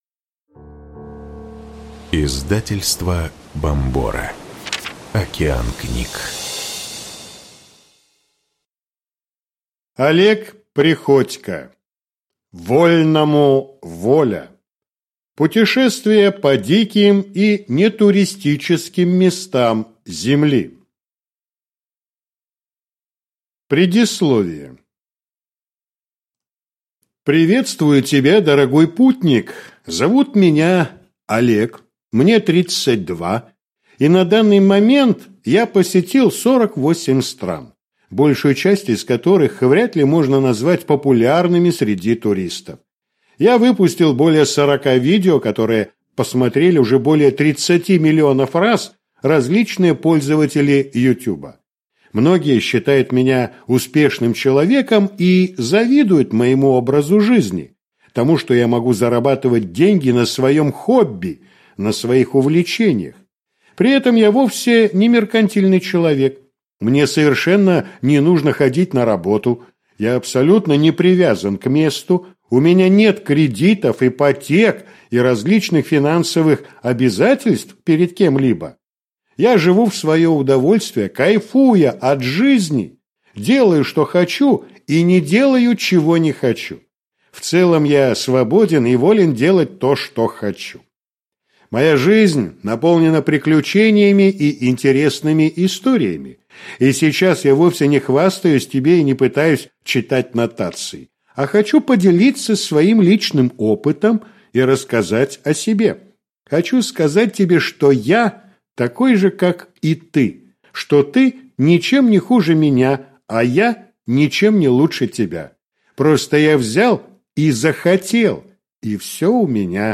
Аудиокнига Вольному – воля. Путешествия по диким и нетуристическим местам Земли | Библиотека аудиокниг